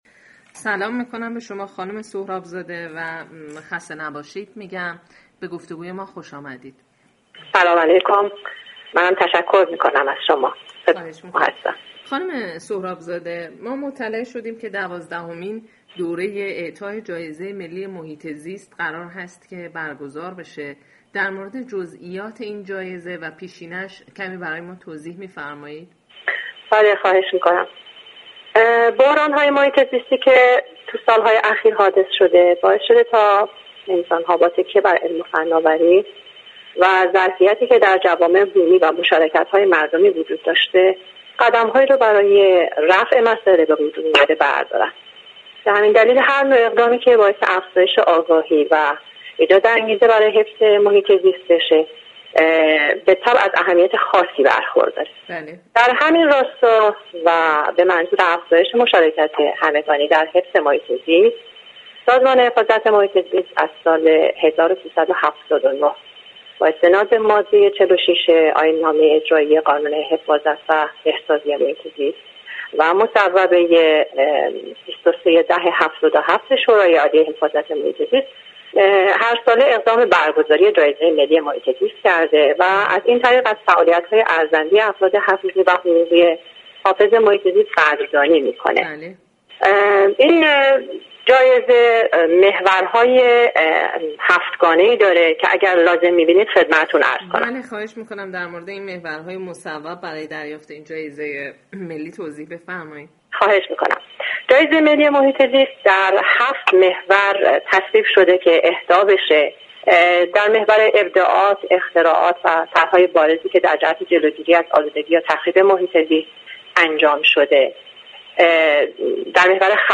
گفتگوی اختصاصی